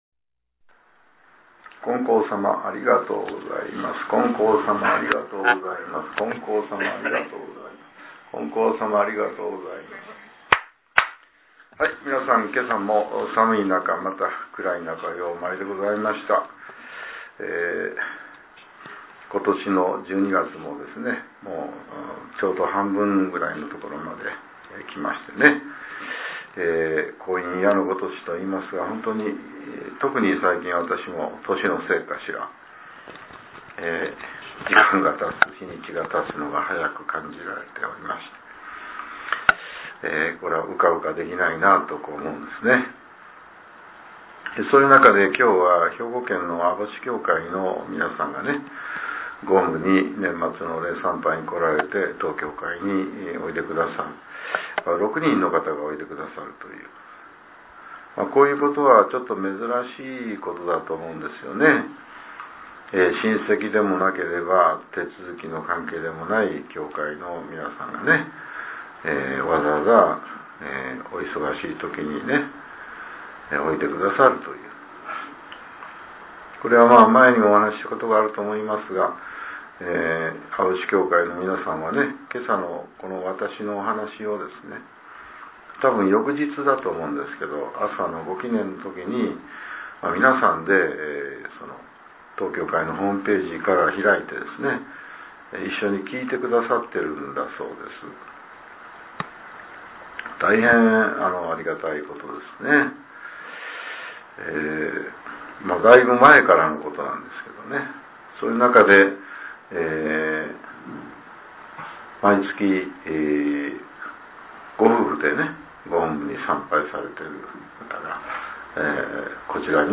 令和６年１２月１４日（朝）のお話が、音声ブログとして更新されています。